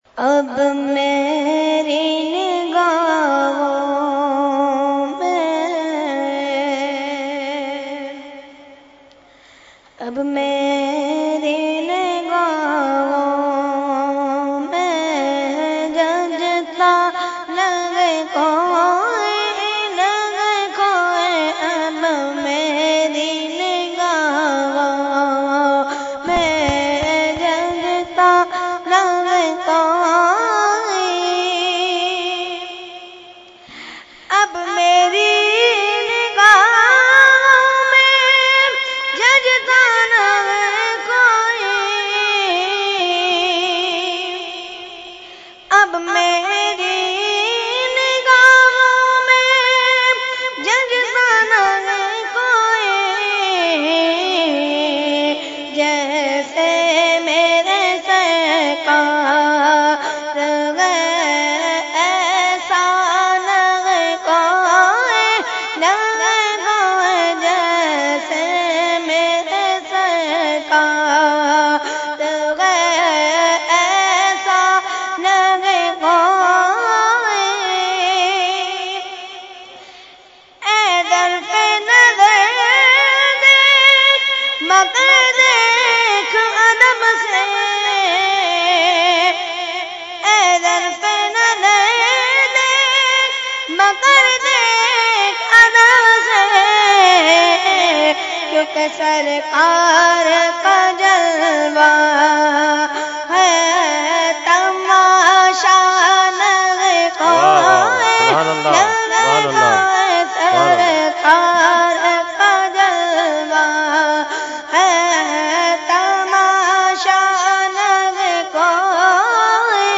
Category : Naat | Language : UrduEvent : Urs Ashraful Mashaikh 2017